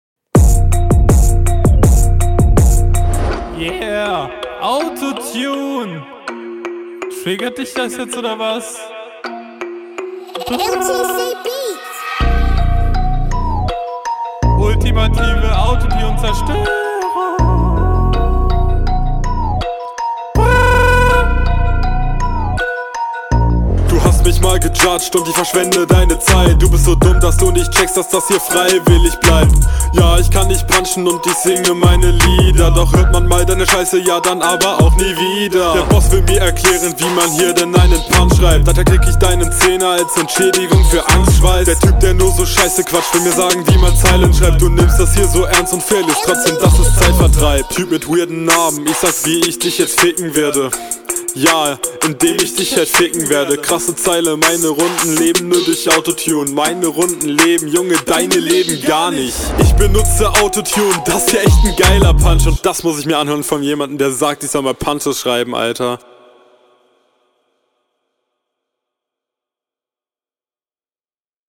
Flow: Der Flow ist auch nichts überragendes.
Flow: Er kommt halt viel besser auf dem Beat, trifft konstant den Takt und hat …